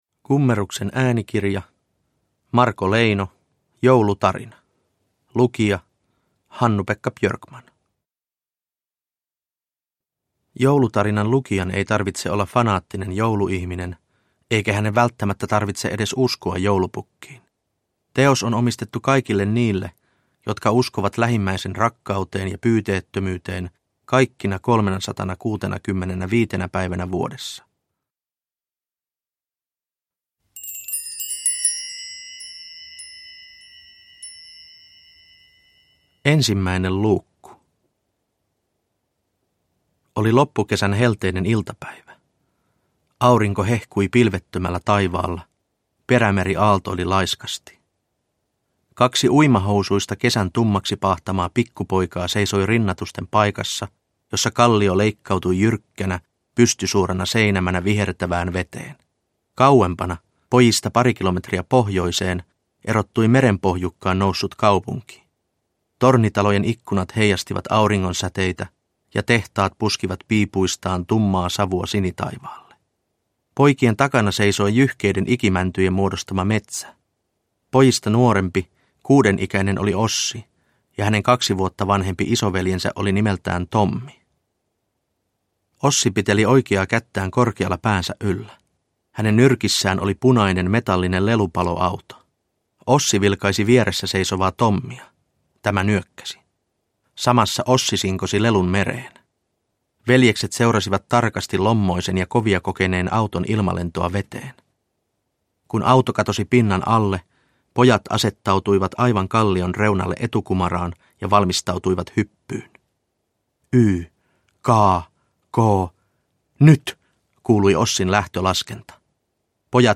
Joulutarina – Ljudbok – Laddas ner
Uppläsare: Hannu-Pekka Björkman